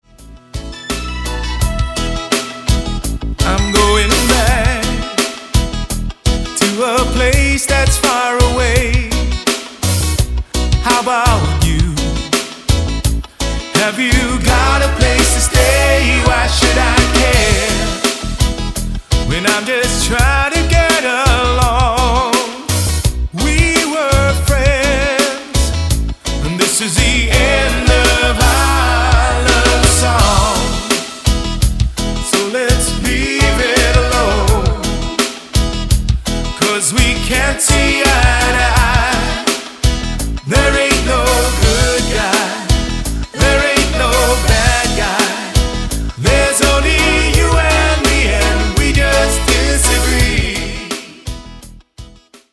Also reggaefied
" demonstrating a grip on expression.